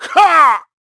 KaselB-Vox_Attack1_kr.wav